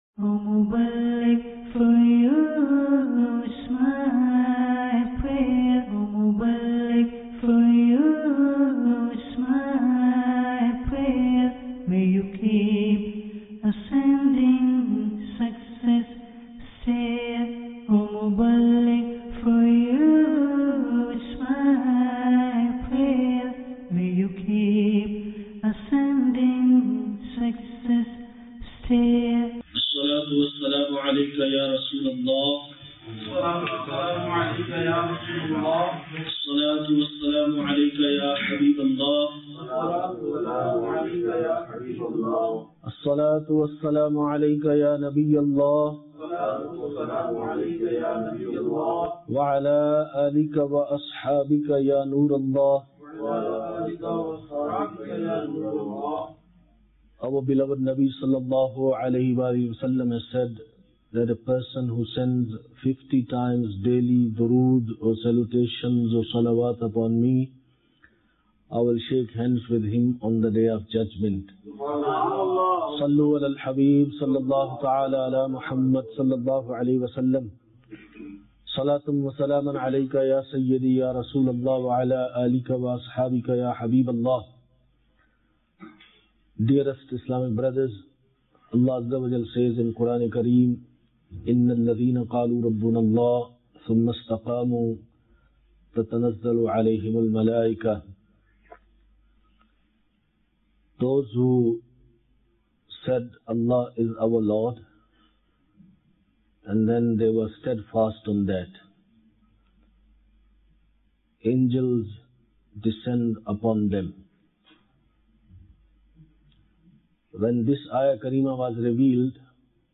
Sunnah Inspired Bayan Ep 237 - Steadfast Is Better Than Miracle Feb 17, 2017 MP3 MP4 MP3 Share Steadfast Is Better than Miracle and this is the quality of the Muslim Ummah that they stay firm on their religion and do not sacrifice their Islamic creeds. There are many definitions given by our ancestors about the steadfastness. watch the complete video clip in order to attain more knowledge.